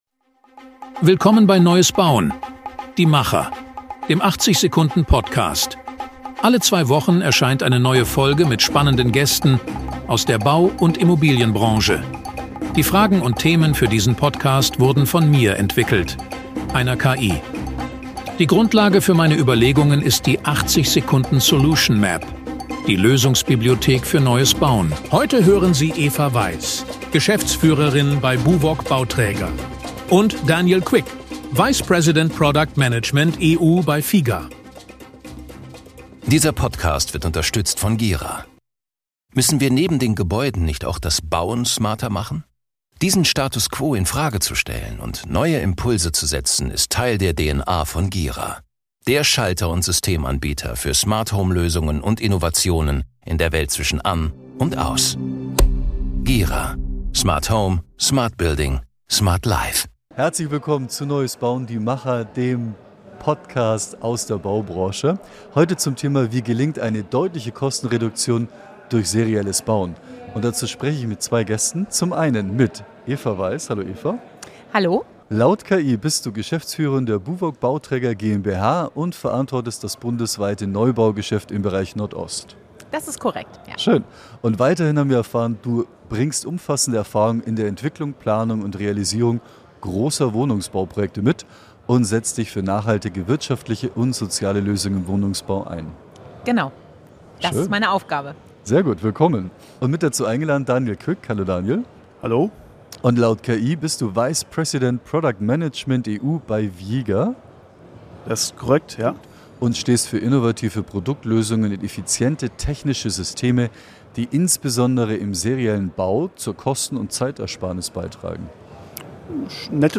Ein Gespräch über Chancen, Grenzen und politische Voraussetzungen für eine echte Bauwende.